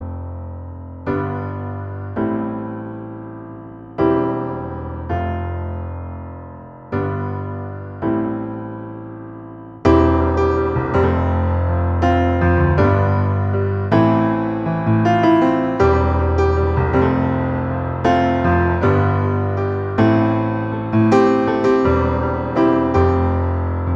Piano Version Pop (2010s) 3:37 Buy £1.50